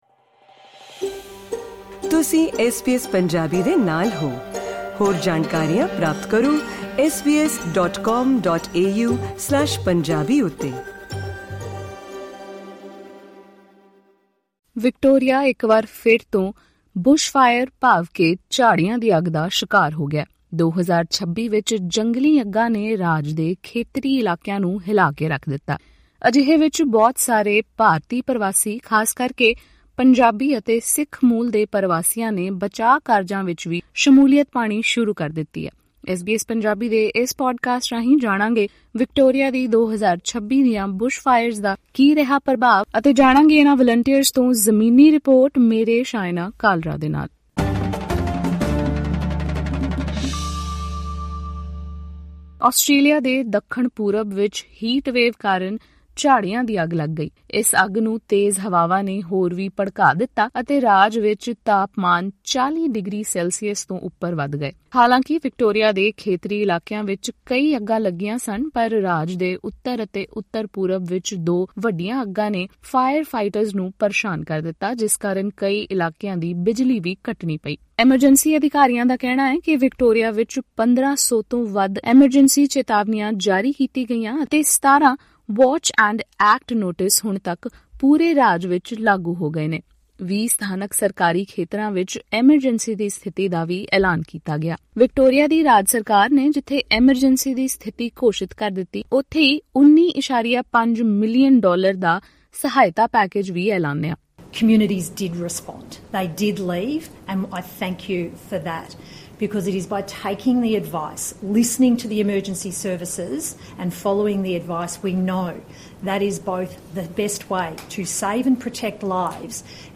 ਇਸ ਭਿਆਨਕ ਘੜੀ ਵਿੱਚ ਵਿਕਟੋਰੀਆ ਦੇ ਪੰਜਾਬੀ ਪਰਵਾਸੀ ਭਾਈਚਾਰੇ ਦੇ ਲੋਕ ਬਚਾਅ ਕਾਰਜਾਂ ਵਿੱਚ ਆਪਣਾ ਸਹਿਯੋਗ ਦੇਣ ਵਾਲਿਆਂ ਵਿੱਚ ਮੋਹਰੀ ਬਣੇ ਹੋਏ ਹਨ। ਇਸ ਪੌਡਕਾਸਟ ਰਾਹੀਂ ਸੁਣੋ, ਵਿਕਟੋਰੀਆ ਦੇ ਖੇਤਰੀ ਇਲਾਕਿਆਂ ਵਿੱਚ ਮੌਜੂਦ ਪੰਜਾਬੀ ਵਲੰਟੀਅਰਾਂ ਨਾਲ ਐਸਬੀਐਸ ਪੰਜਾਬੀ ਦੀ ਗੱਲਬਾਤ।